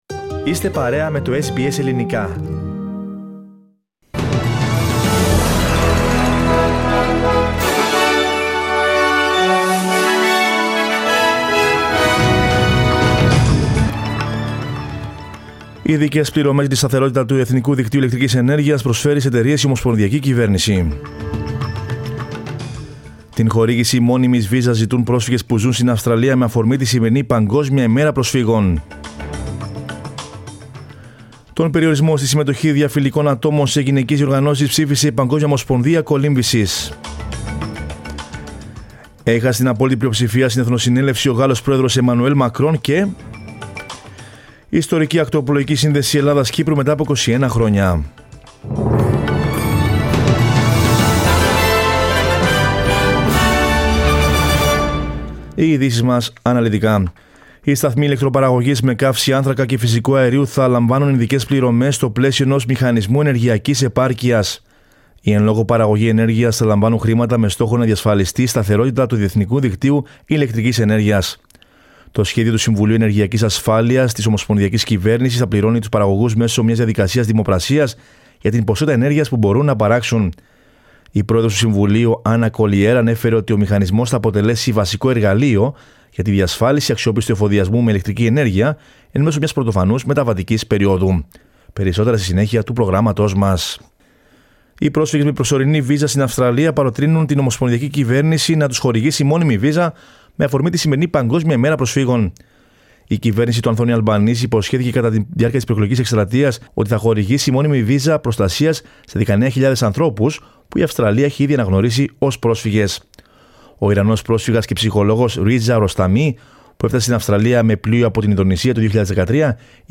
News in Greek from Australia, Greece, Cyprus and the world is the news bulletin of Monday 20 June.